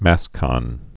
(măskŏn)